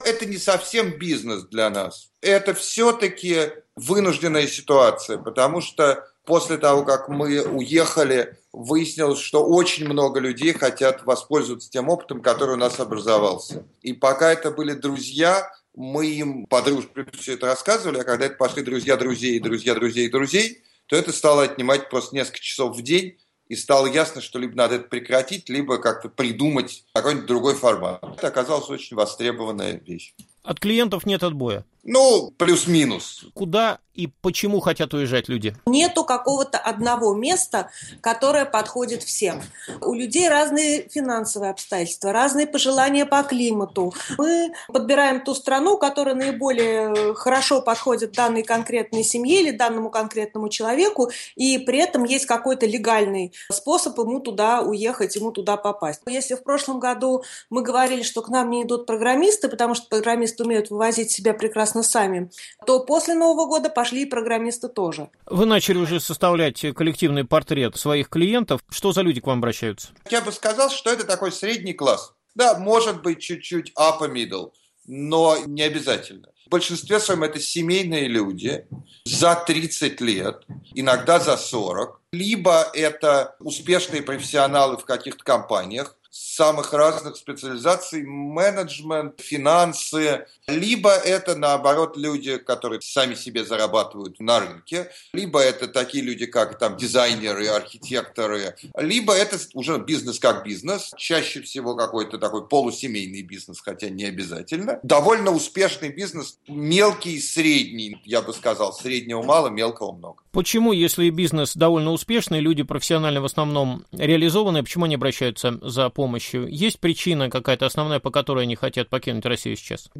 Россия уезжает из России? Интервью с консультантами по эмиграции